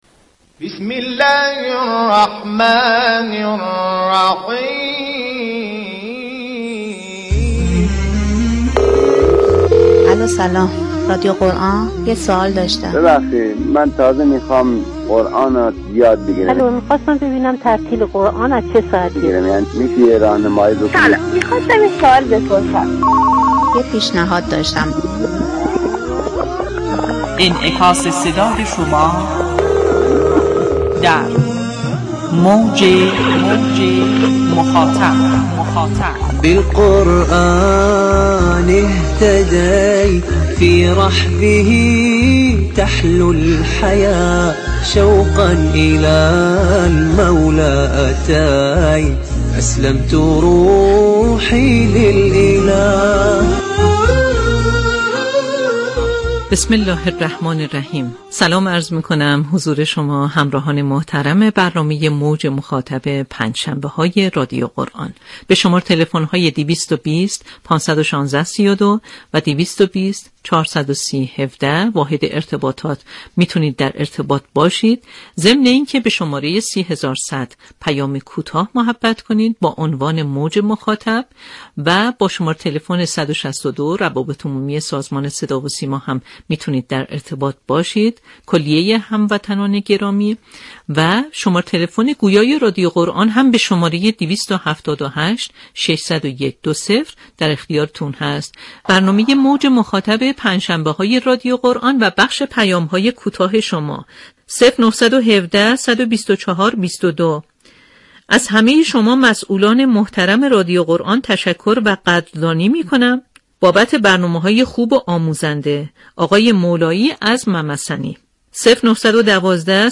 همچنین گفت‌وگو با مدیران گروه‌های برنامه‌ساز و اطلاع‌رسانی برنامه‌های جدید در ایام و مناسبت‌های مختلف، ارتباط با گزارشگران مراكز شهرستان‌ها و اطلاع از برنامه‌های مراكز مختلف از دیگر بخش‌های برنامه خواهد بود.